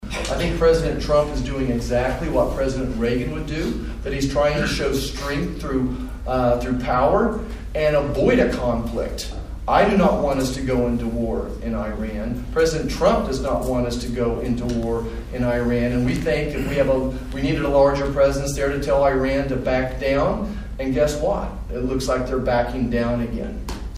MANHATTAN — Congressman Roger Marshall was back in Manhattan Saturday, hosting a town hall discussion with about two dozen constituents at the Sunset Zoo’s Nature Exploration Place.